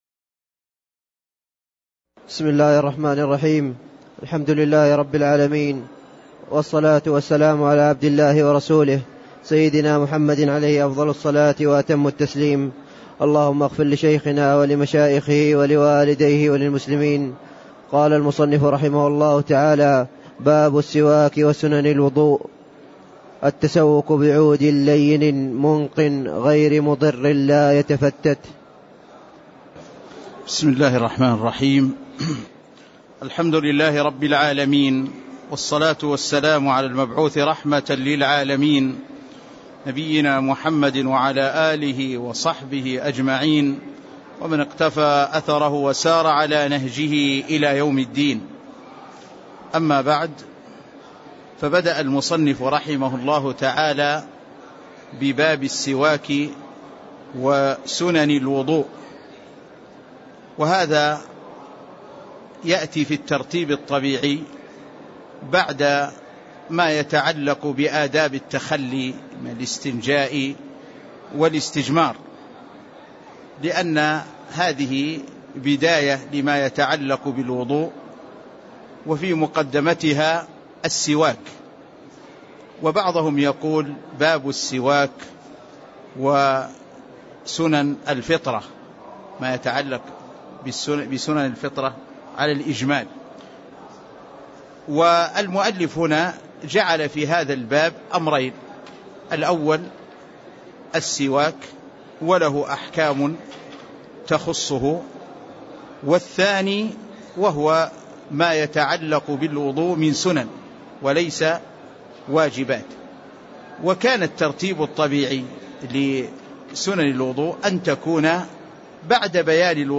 تاريخ النشر ١ جمادى الأولى ١٤٣٥ هـ المكان: المسجد النبوي الشيخ